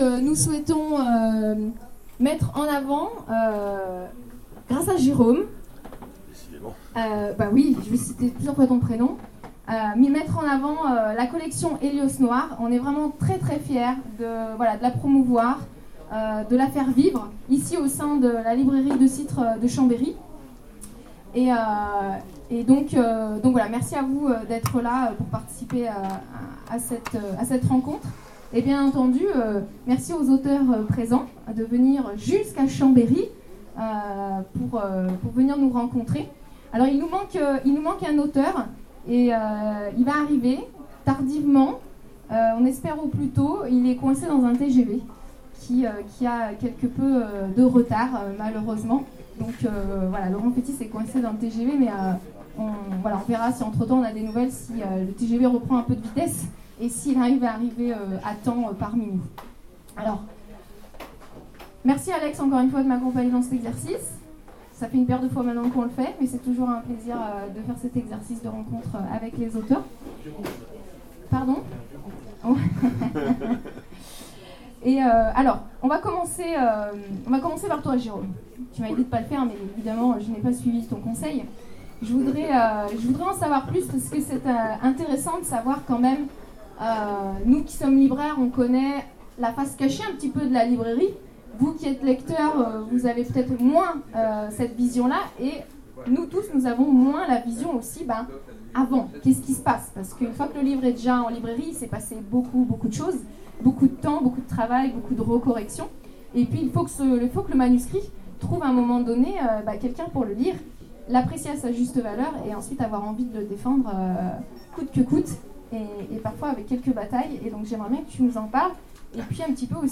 Retour sur la rencontre Hélios noir du 25 juin 2016
Interview